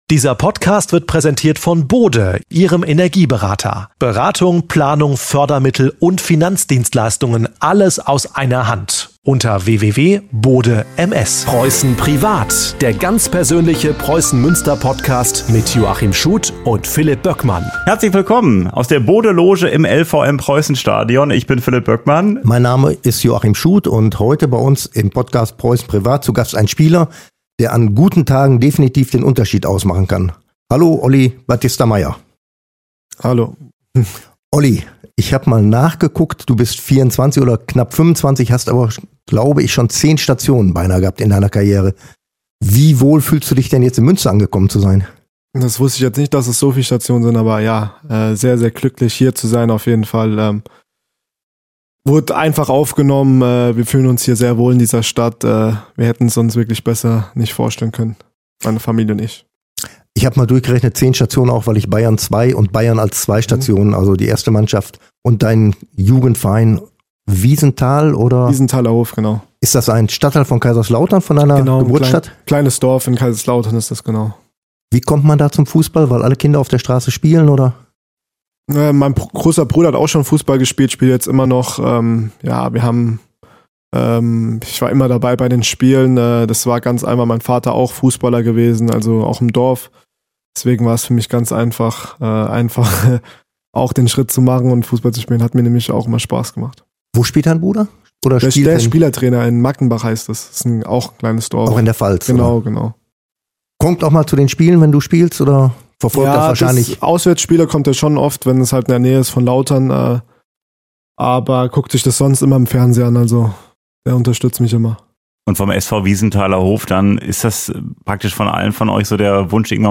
Ein Gespräch über Fußball, Heimat und das Leben abseits des Platzes.